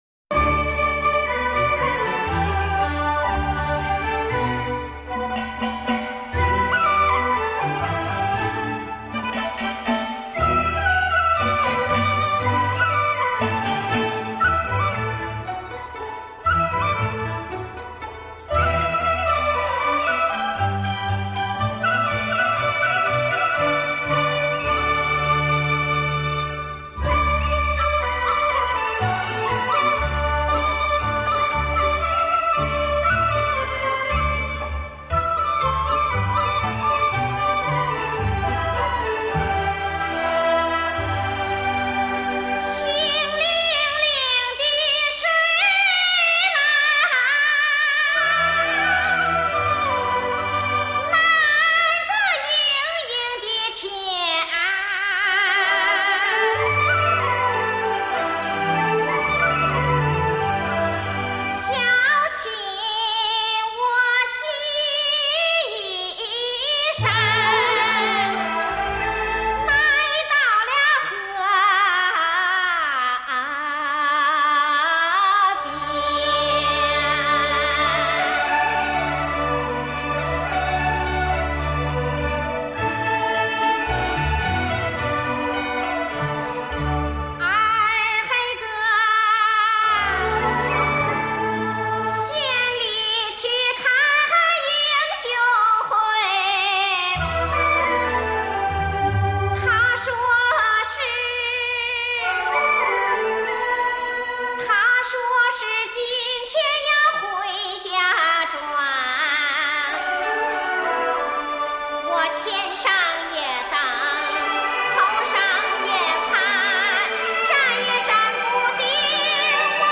嗓音甜美，音域宽阔，吐字清晰，行腔富于韵味，具有浓郁的中国民族歌唱特色，